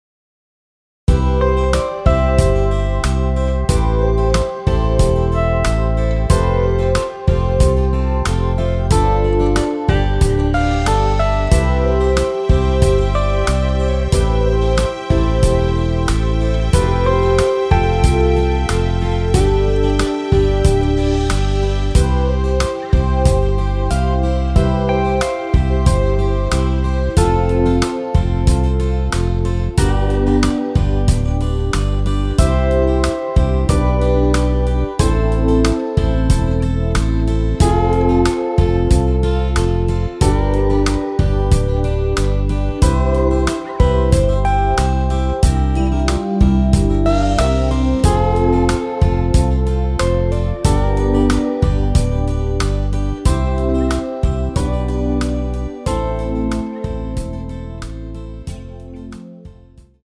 MR 입니다.
원키에서(+5)올린 MR입니다.
앞부분30초, 뒷부분30초씩 편집해서 올려 드리고 있습니다.
중간에 음이 끈어지고 다시 나오는 이유는